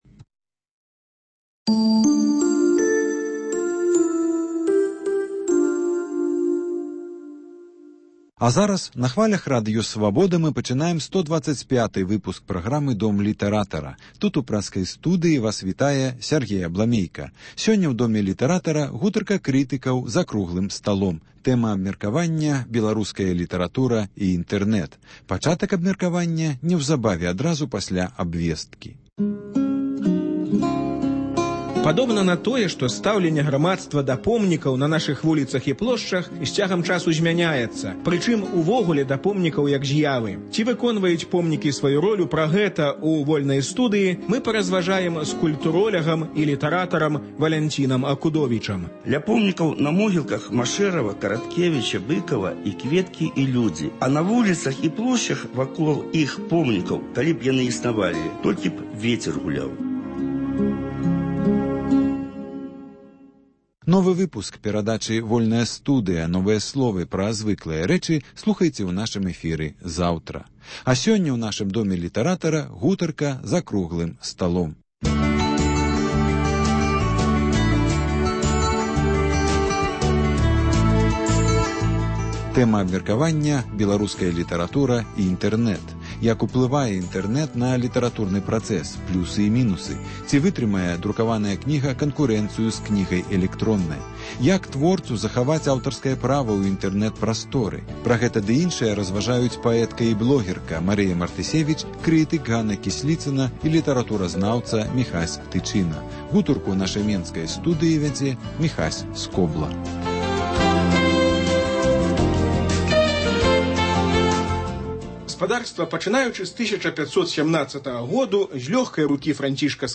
Круглы стол